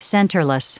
Prononciation du mot centerless en anglais (fichier audio)
Prononciation du mot : centerless